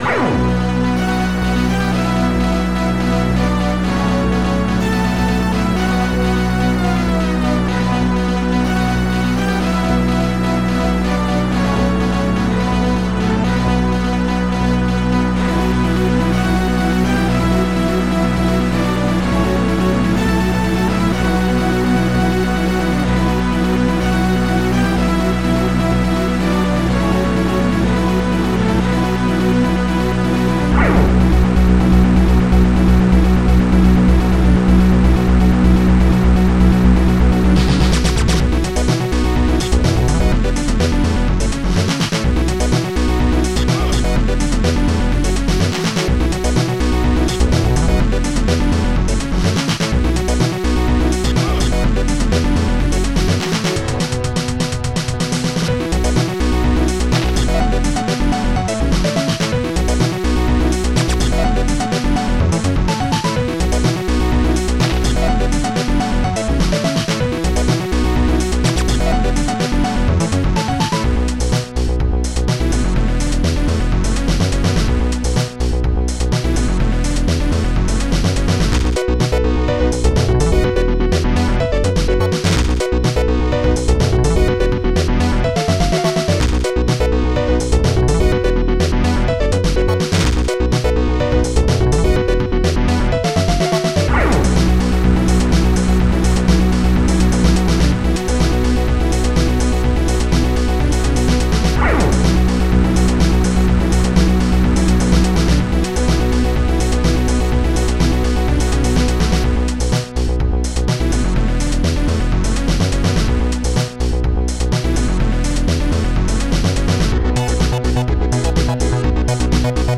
Protracker Module  |  2000-10-02  |  99KB  |  2 channels  |  44,100 sample rate  |  5 minutes, 25 seconds
D-50-EchoBass
d20synth1
woodsnare
Dtpiano